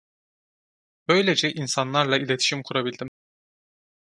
Pronounced as (IPA)
/i.le.ti.ʃim/